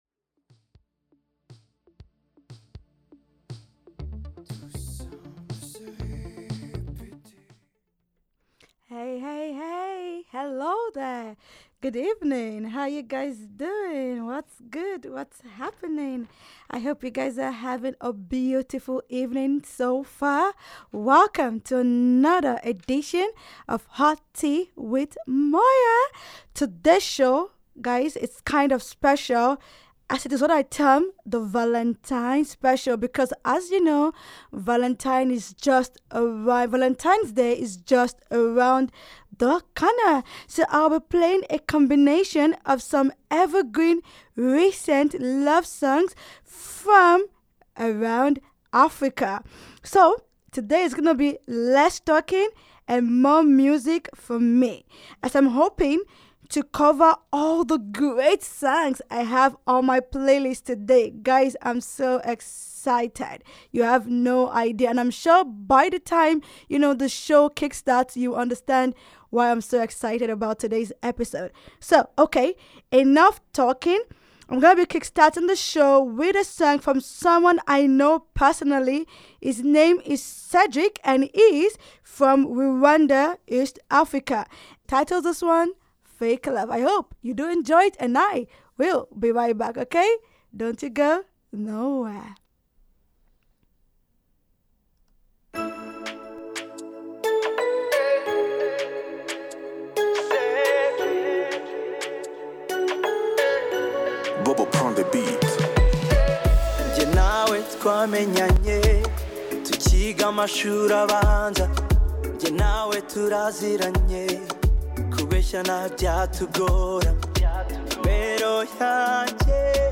International, World-Beat, Hip-Hop, Indie, Pop